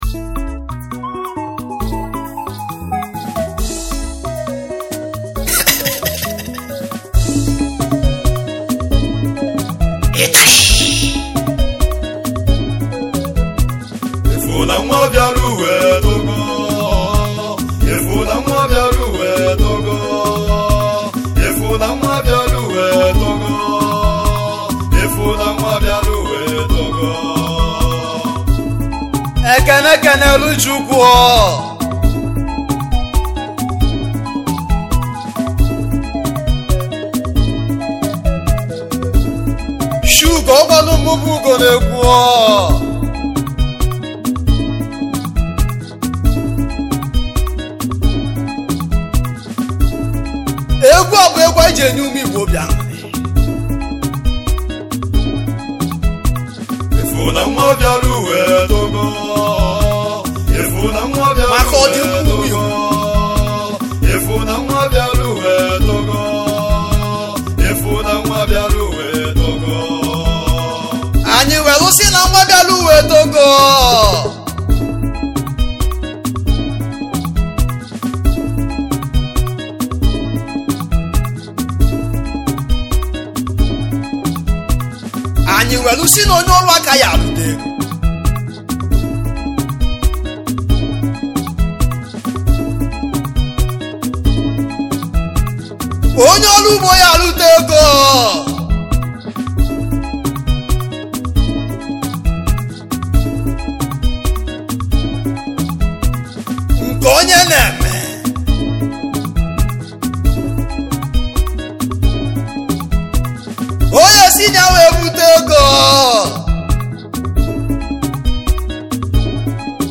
igbo highlife